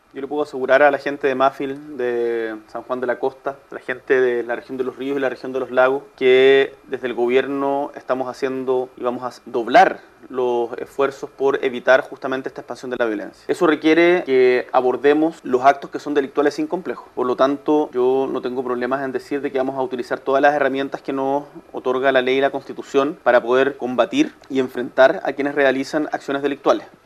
Recordemos que el Presidente Boric se encuentra en la región de Los Lagos, en el marco del segundo Foro de Descentralización que se desarrolla en Frutillar.
Previo a este encuentro, el mandatario señaló que desde el Gobierno redoblarán los esfuerzos para evitar la expansión de la violencia.